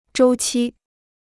周期 (zhōu qī): période; cycle.